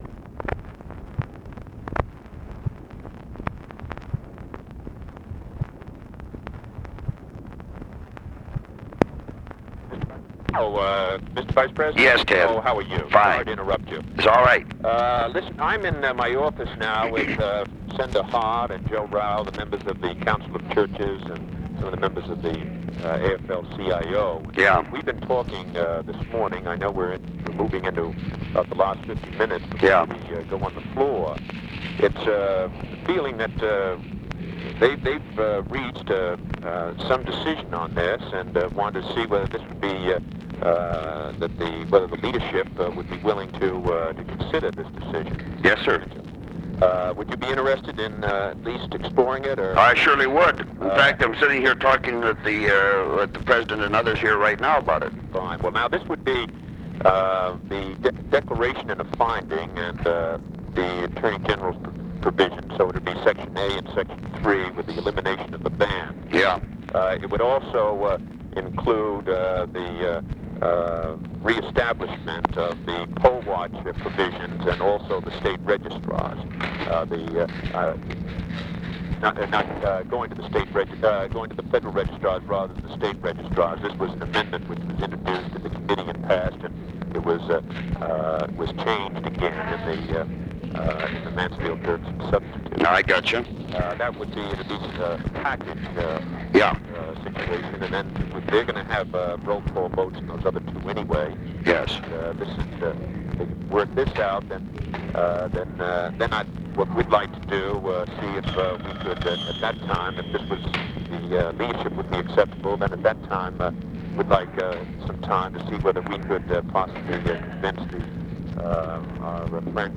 Conversation with EDWARD KENNEDY, May 11, 1965
Secret White House Tapes